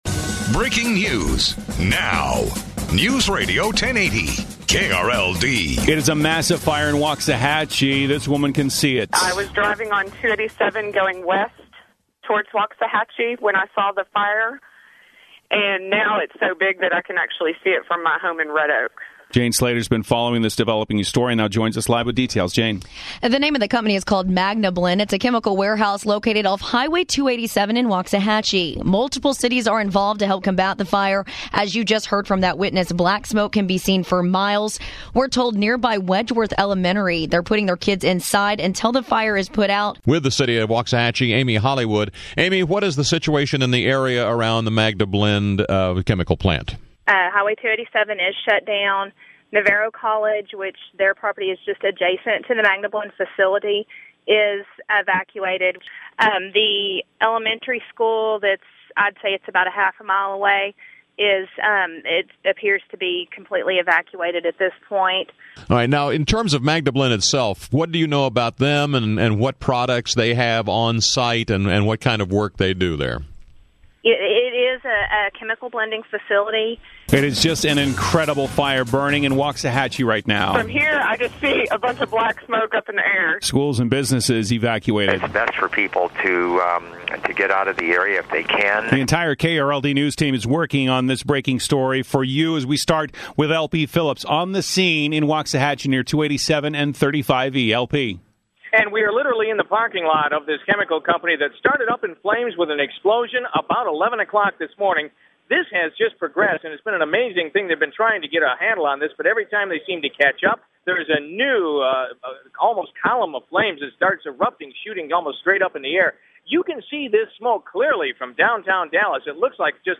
Murrow-Chemical Plant Fire-Audio Breaking News Coverage